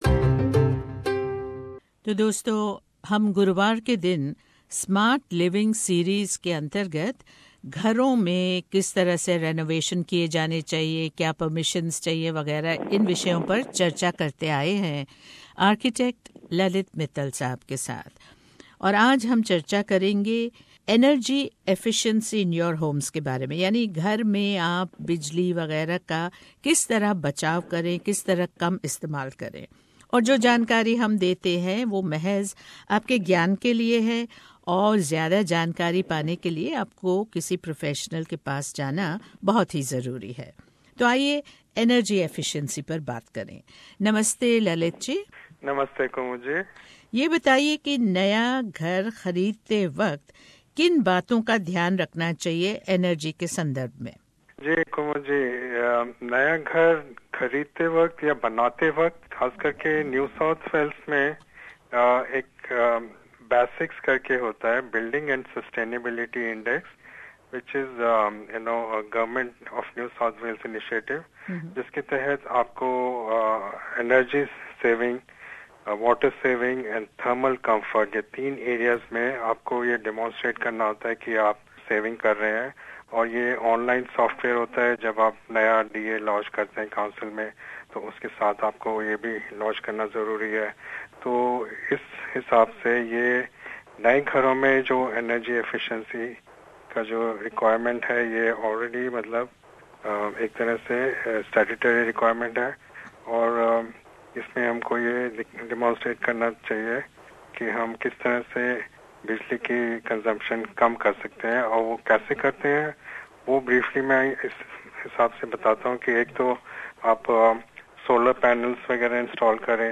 पेश है ये भेंटवार्ता